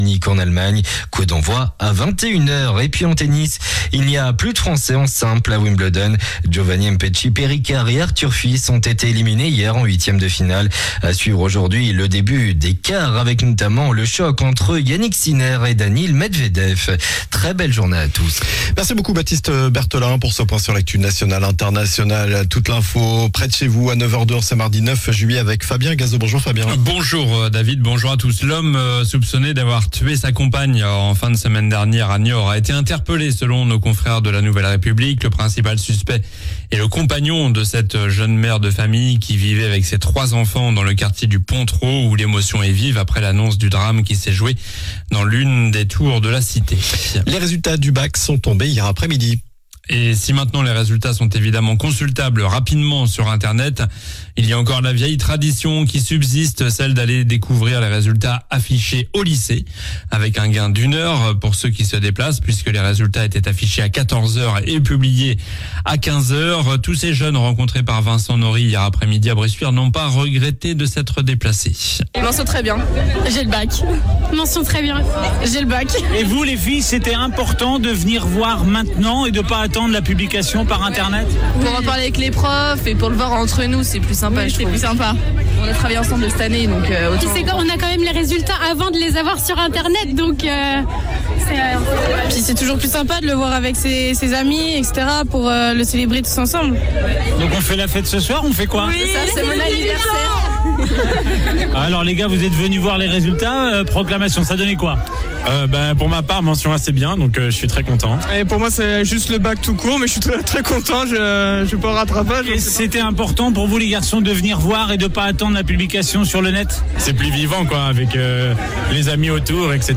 Journal du mardi 09 juillet (matin)